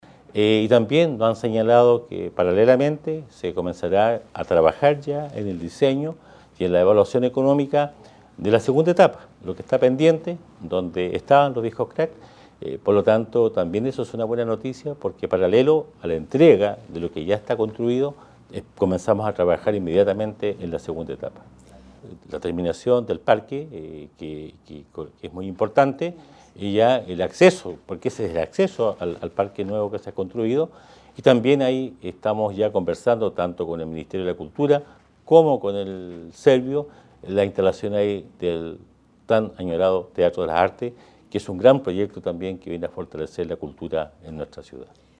El alcalde Emeterio Carrillo valoró que la iniciativa ya tenga una fecha más clara de apertura a la ciudadanía.